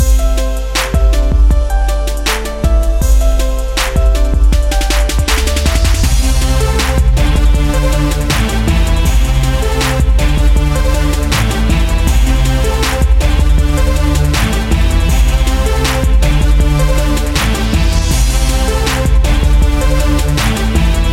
with sweet voice
标签： 100 bpm Rap Loops Groove Loops 3.56 MB wav Key : Unknown
声道立体声